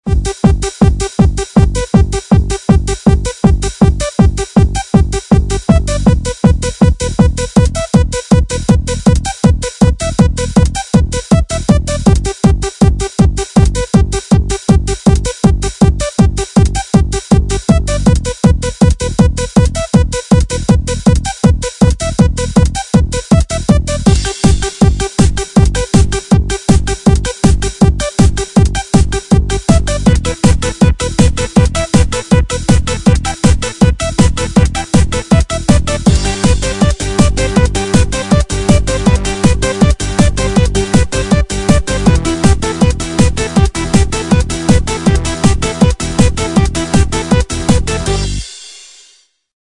Melodía del gracioso anuncio de televisión